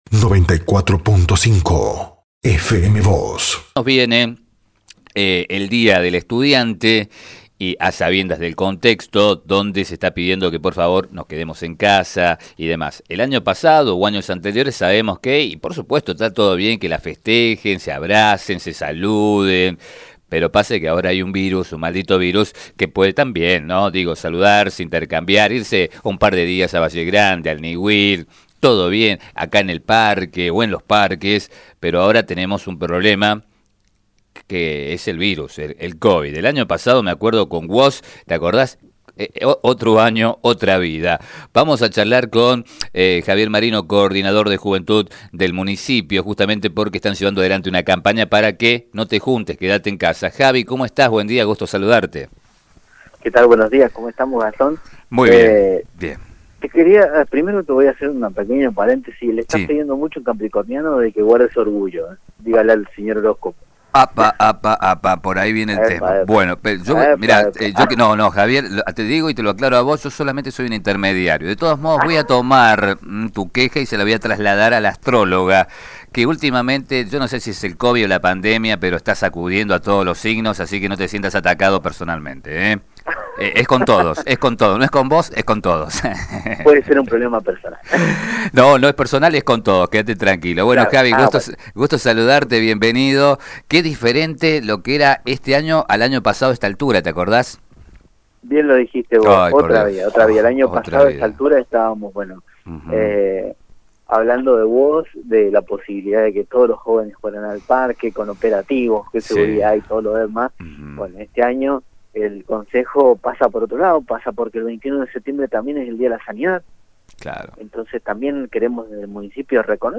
Desde FM Vos (94.5) y Diario San Rafael dialogamos